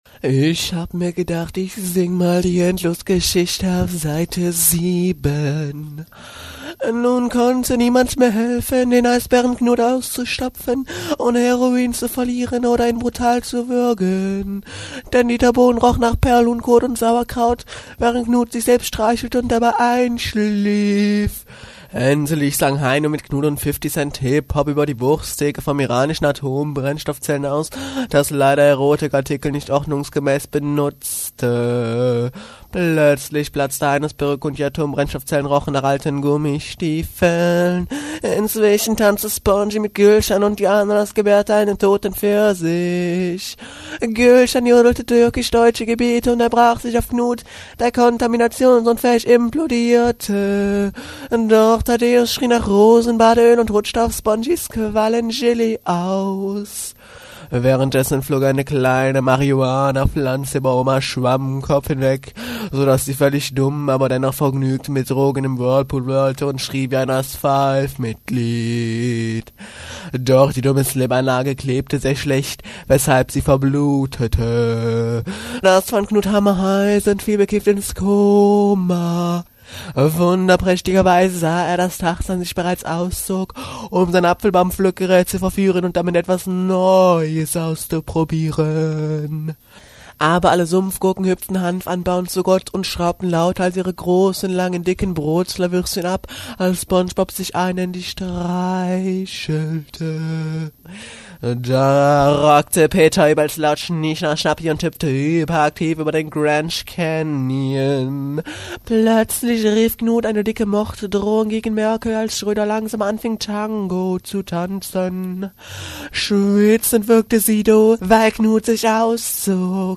Das Hörbuch zur endlosen Story, Seite 7